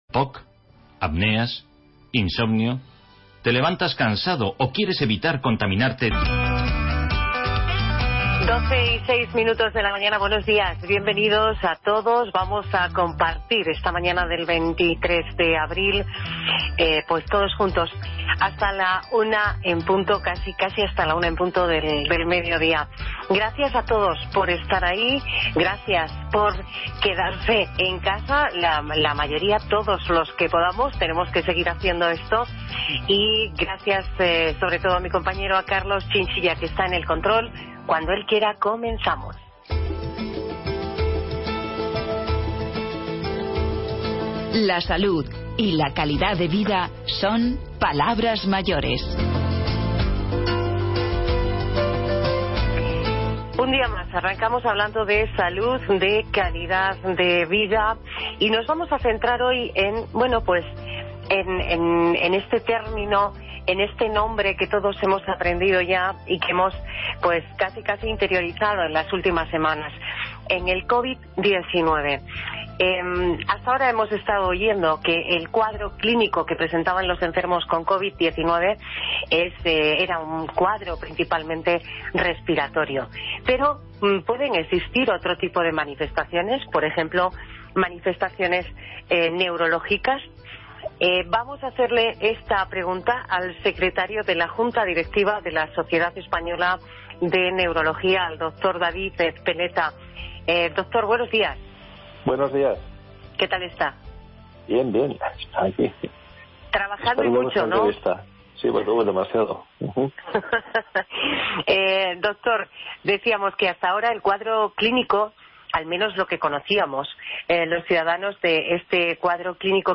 Hablamos con la cantante Soraya Arnelas, quien nos presenta su trabajo Luces y sombras y nos cuenta cómo se fraguó este nuevo disco con tintes biográficos.